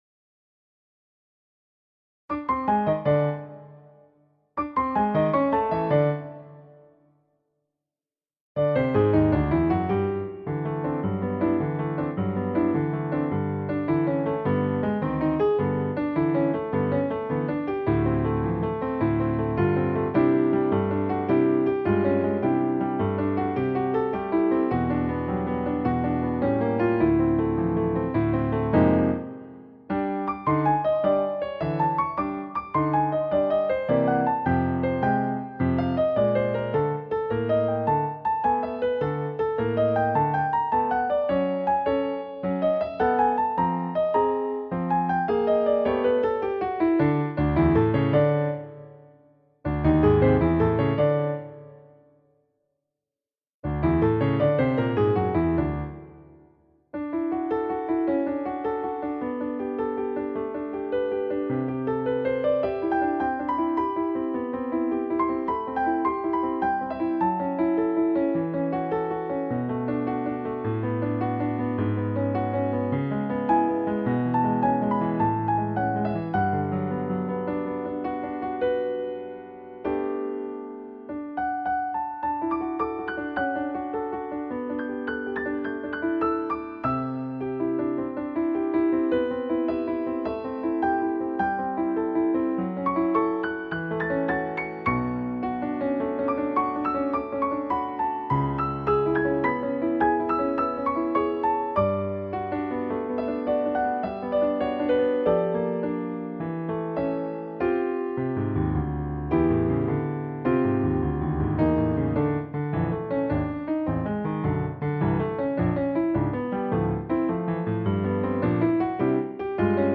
Games piano slower